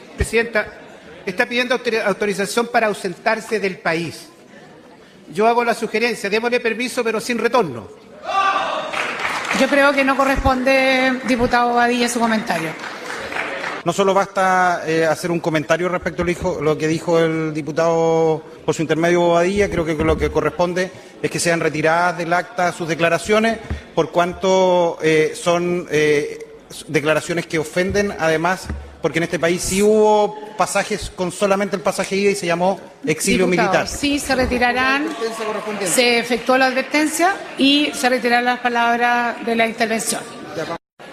Pese a la amplia aprobación, la discusión estuvo marcada por un tenso intercambio en la Sala, luego de un comentario realizado por el diputado Sergio Bobadilla.
La respuesta vino desde el diputado PS Daniel Manouchehri, quien recordó que en Chile los “viajes de ida” estuvieron históricamente asociados al exilio.